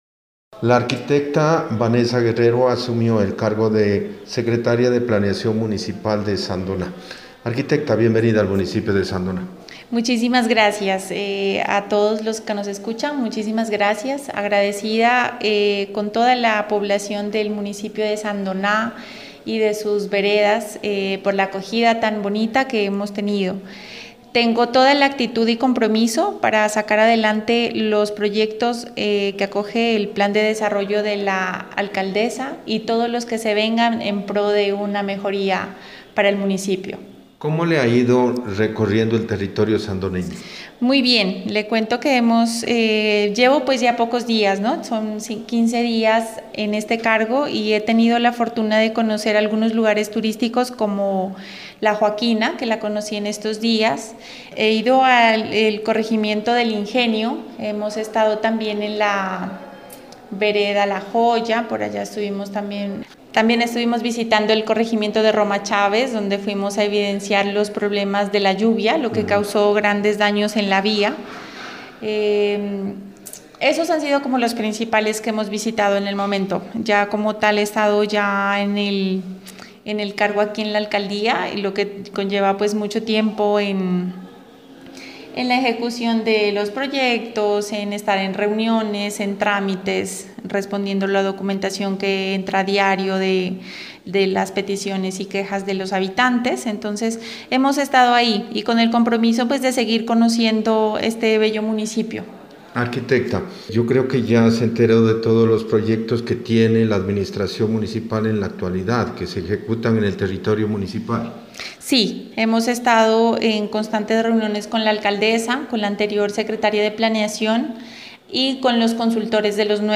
Entrevista con la arquitecta Vanessa Guerrero.